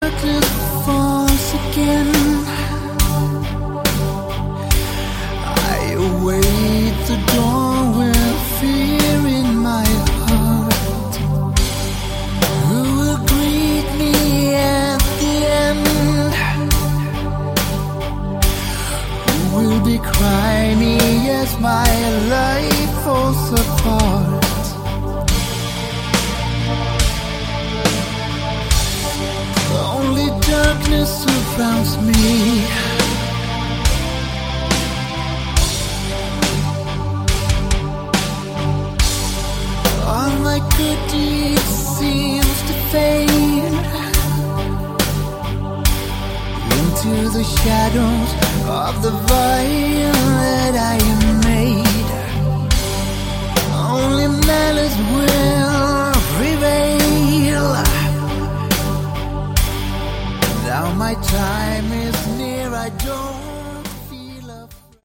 Category: Hard Rock/Melodic Metal
vocals, keyboards
guitars
bass
drums